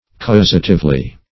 \Caus"a*tive*ly\